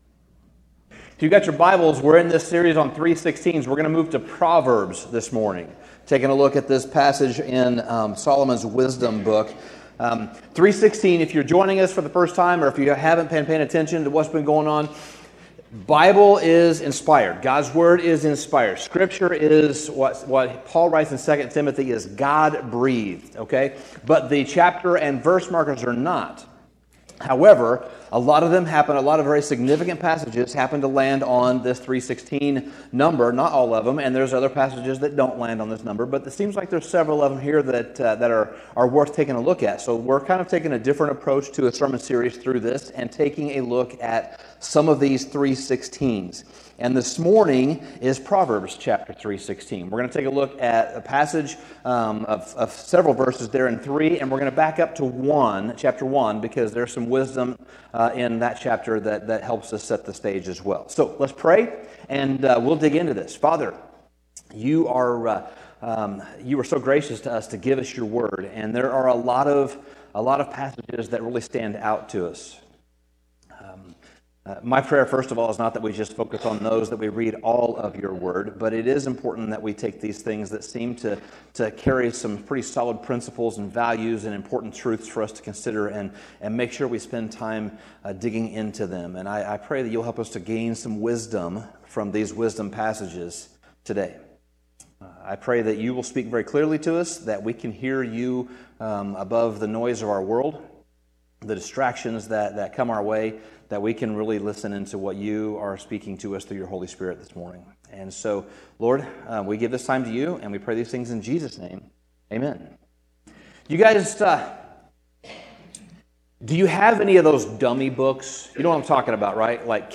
Sermon Summary Solomon wrote much of the book of Proverbs, and in the first few chapters he describes wisdom in detail, exploring what it is, why it's necessary, and how to obtain it.